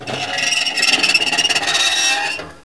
Metallo graffiato
Rumore di metallo che viene raschiato con un altro metallo.
SCRAPER2.WAV